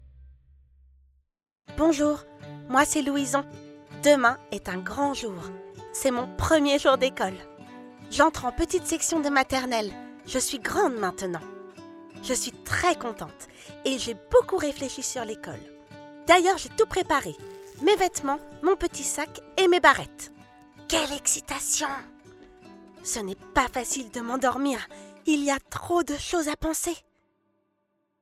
Get £0.60 by recommending this book 🛈 Une histoire sur le premier jour d'école, lu par plusieurs comédiens, illustré de musiques et ambiances sonores pour les plus petits ! Demain est un jour très attendu par Louison : c'est son premier jour d'école !